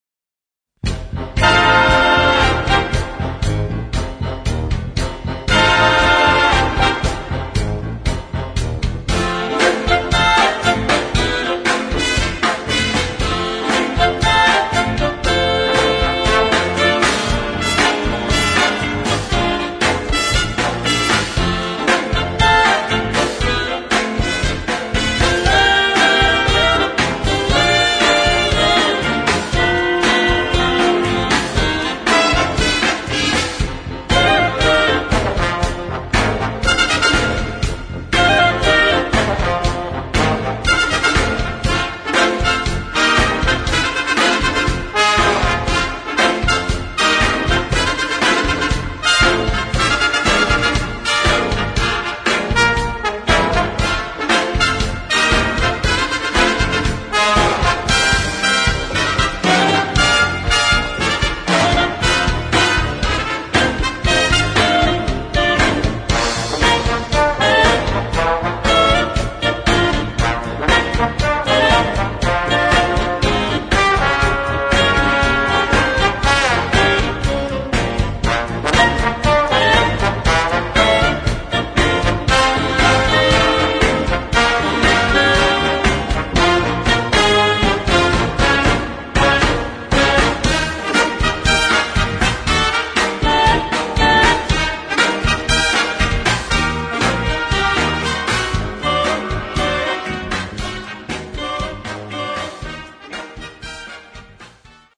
Partitions pour orchestre d'harmonie et fanfare.